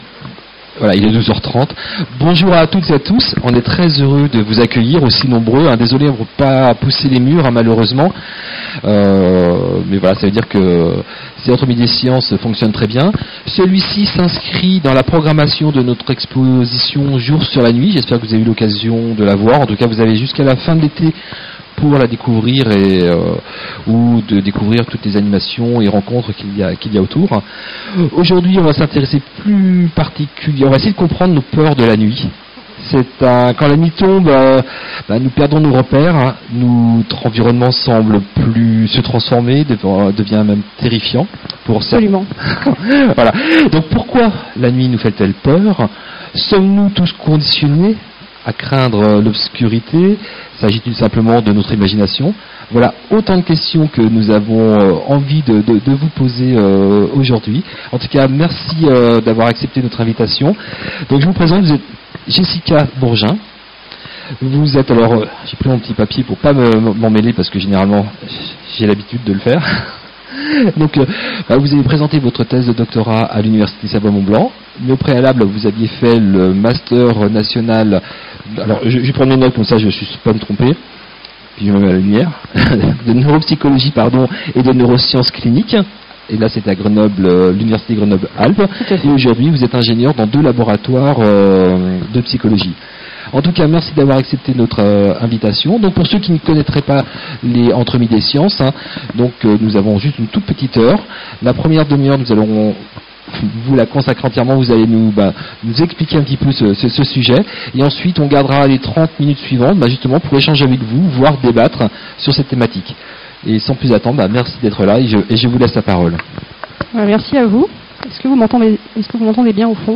" Quand tombe la nuit : nos peurs prennent-elles le dessus ? " - Retour sur le café débat Entre midi & science du jeudi 12/03/2026
Ecoutez le café débat et retrouvez toutes les questions du public sur l'enregistrement audio .